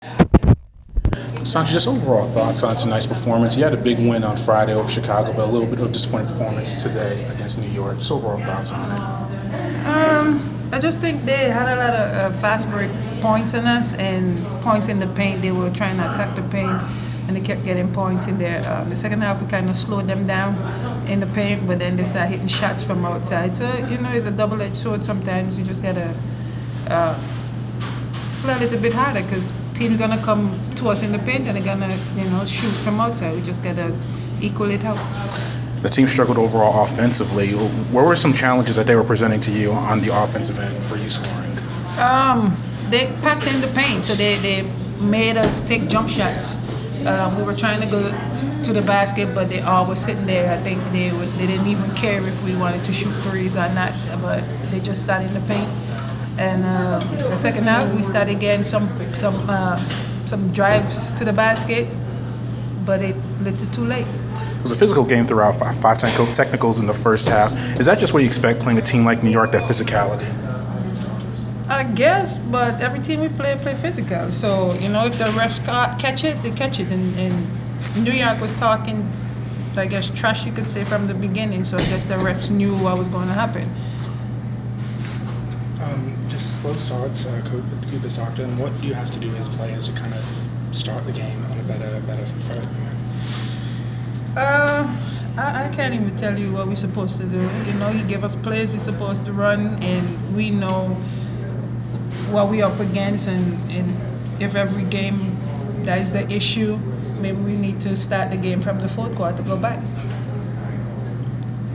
Inside the Inquirer: Postgame interview with Atlanta Dream player Sancho Lyttle 6/20/15